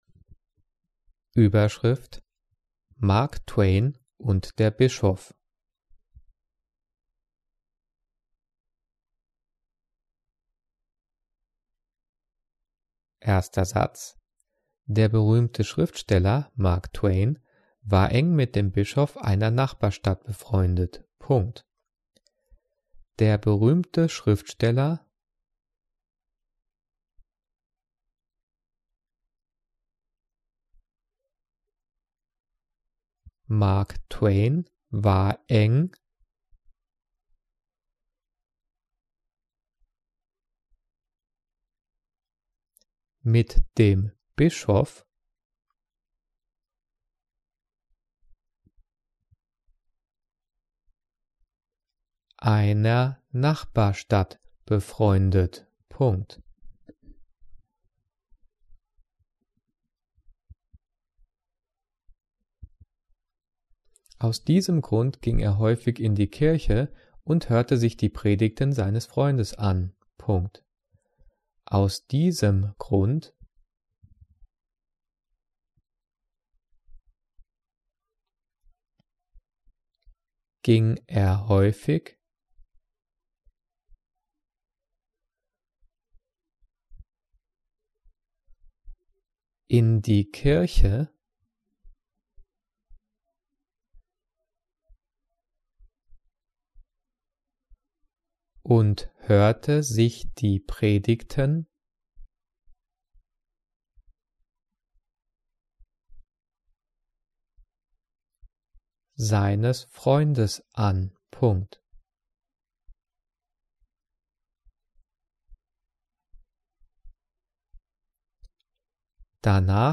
Die vielen Sprechpausen sind dafür da, dass du die Audio-Datei pausierst, um mitzukommen.
Übrigens, die Satzzeichen werden außer beim Thema "Zeichensetzung" und den Übungsdiktaten der 9./10. Klasse mitdiktiert.
Diktiert: